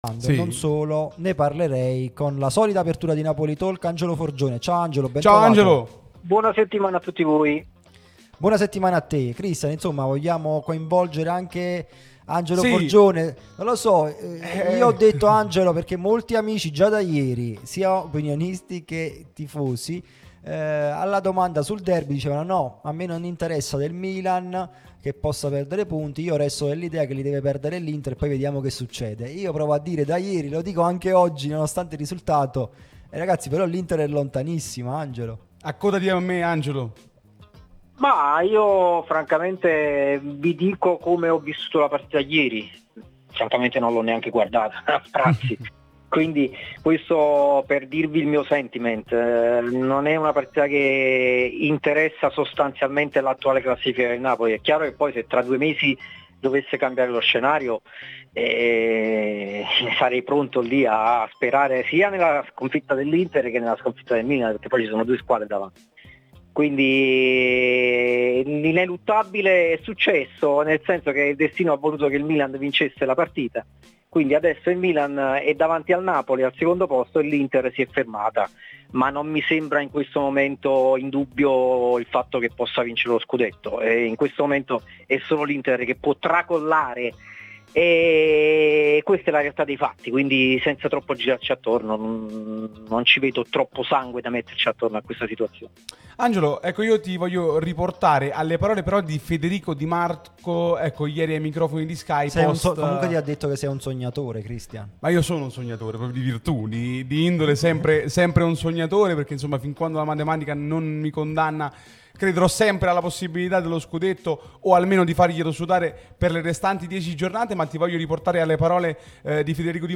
prima radio tematica sul Napoli